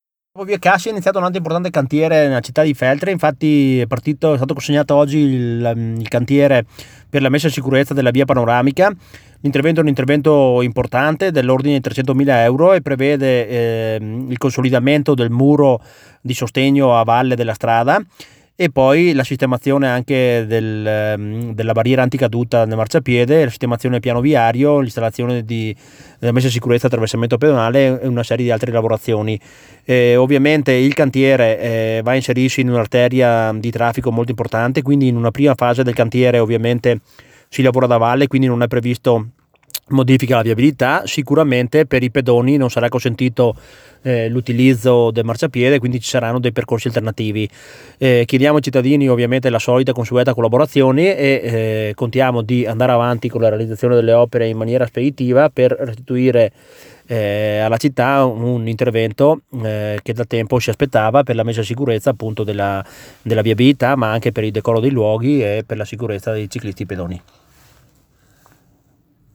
L’ASSESSORE AI LAVORI PUBBLICI ADIS ZATTA